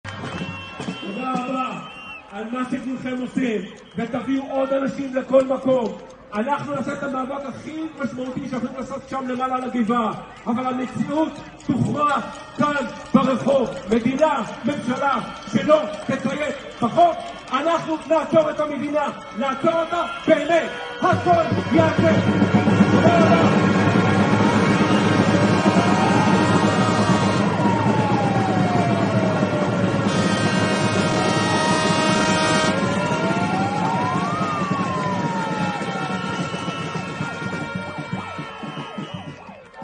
ח״כ יואב סגלוביץ׳ בהפגנה מול משרד ראש הממשלה: